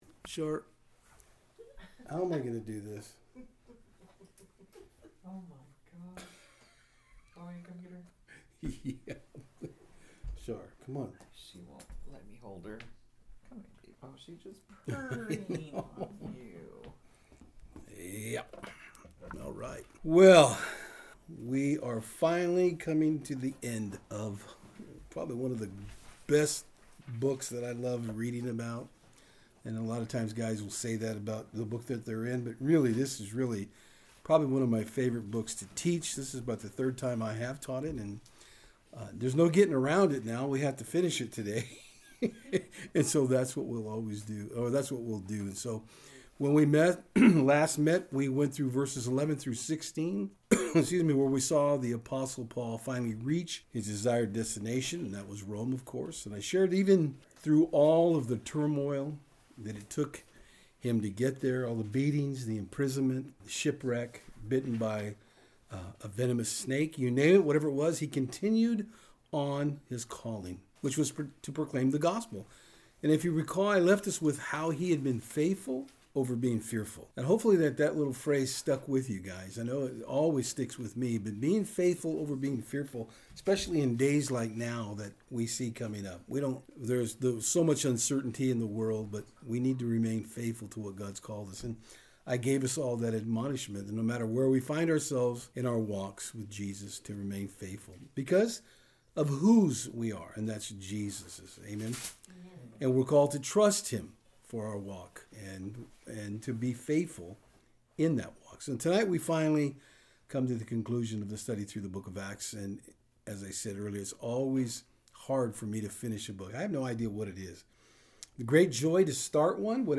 In our final message through the book of Acts we see how Paul was confined to his home unable to leave but that didn’t stop him form preaching and teaching. Where ever we may find ourselves in life it is always good to know that we are all called to share the gospel message with those who we come in contact with. Paul ends his time in Rome confined and still writing letters and teaching about his favorite love, Jesus!